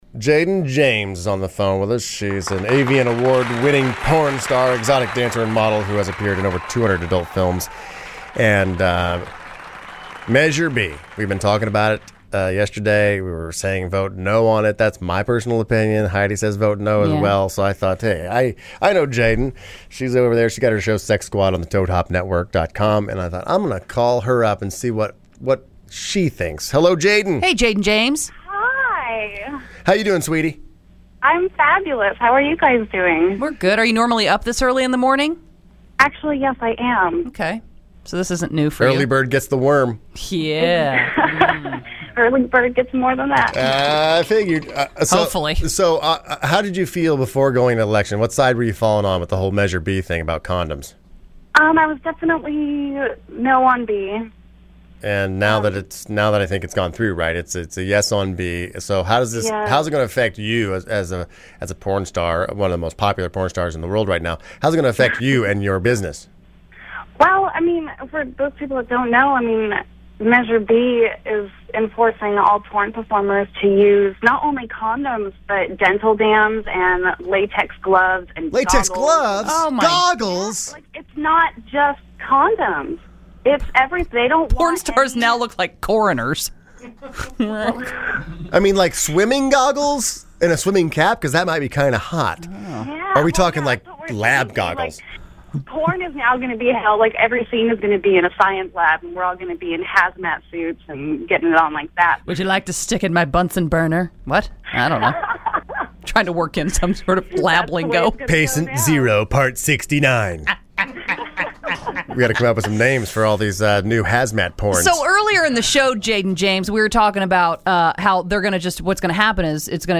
Listen November 07, 2012 - Interview - Jayden Jaymes - The Heidi & Frank Show